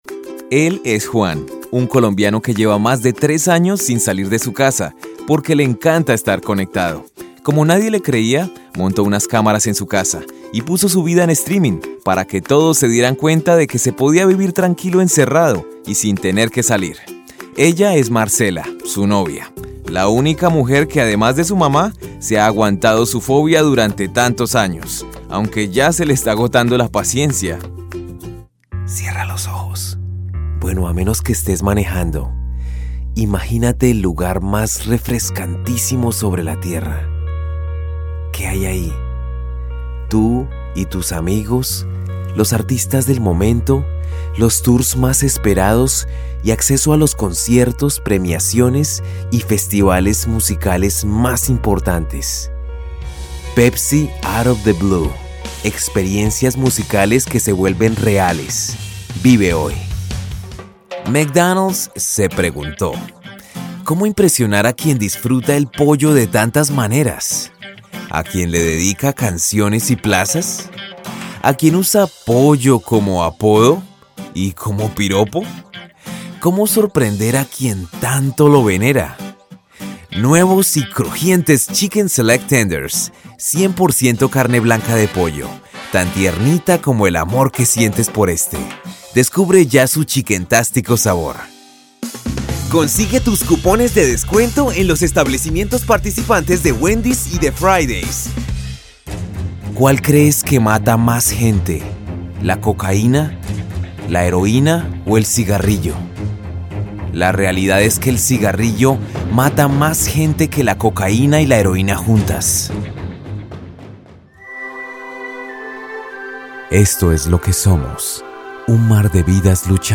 Non English Male